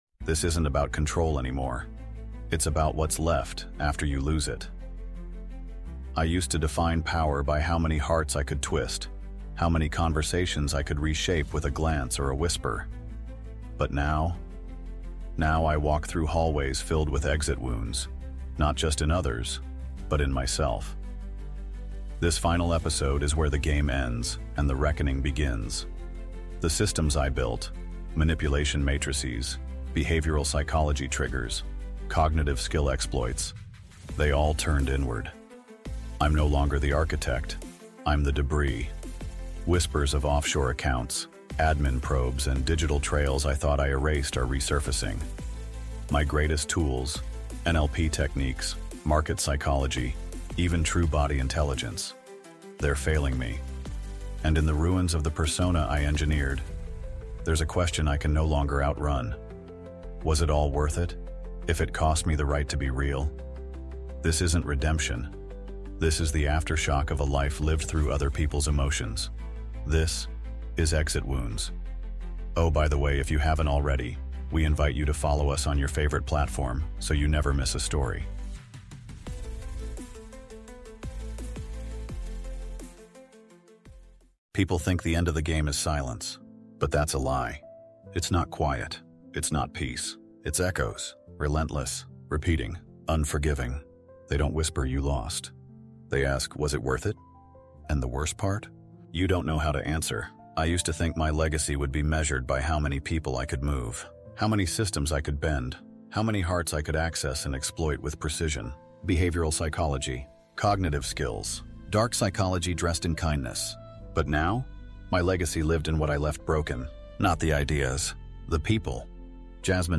Inside the Mind of a Master Manipulator | Exit Wounds | Audiobook